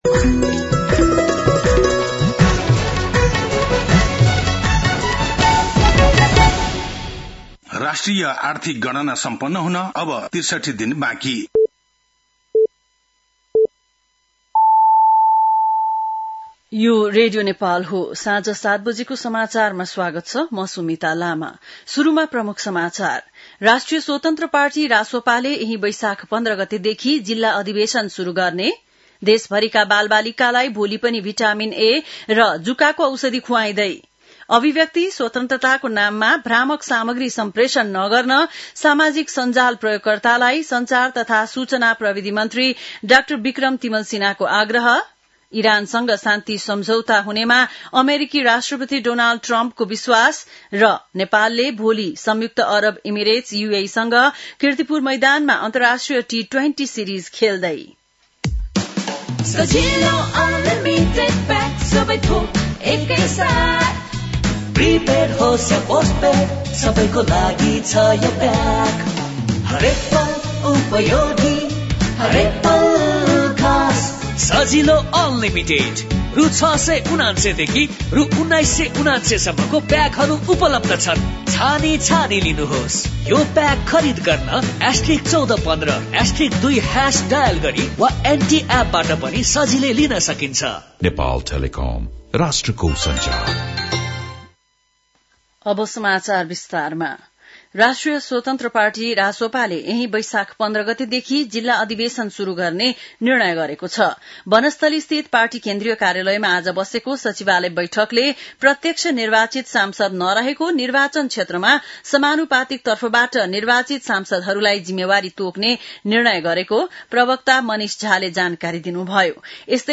बिहान ७ बजेको नेपाली समाचार : ६ वैशाख , २०८३